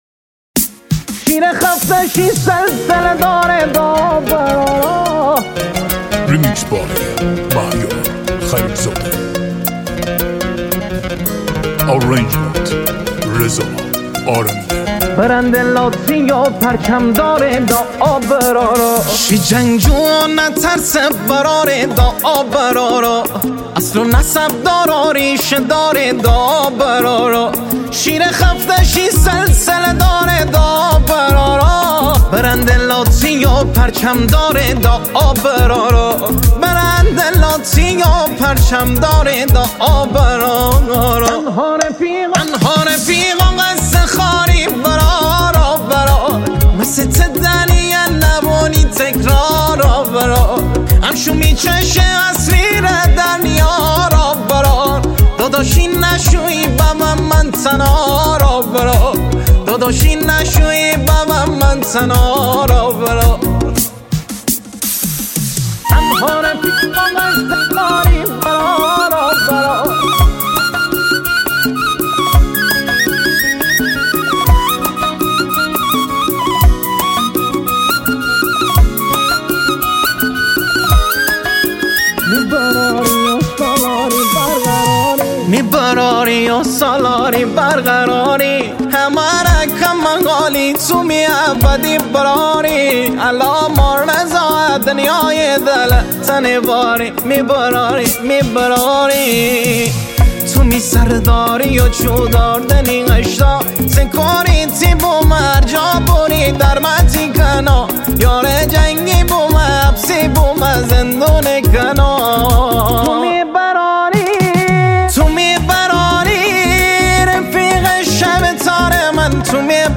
آهنگ شمالی